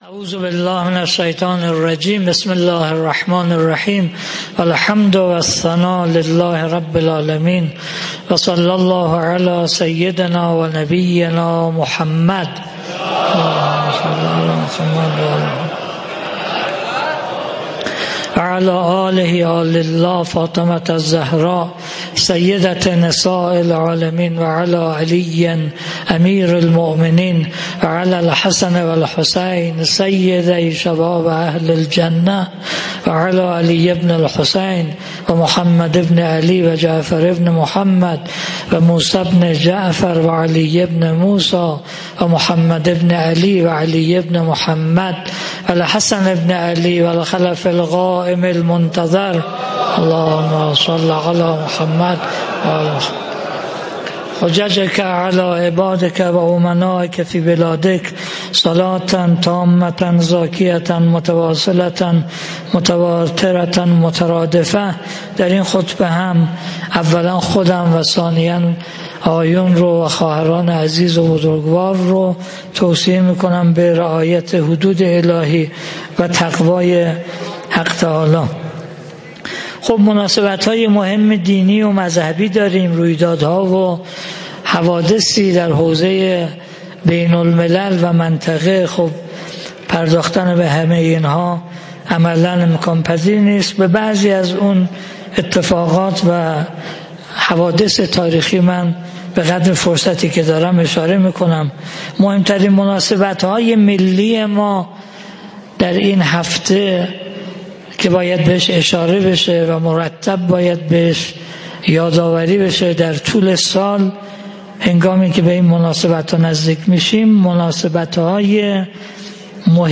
خطبه-دوم_01.mp3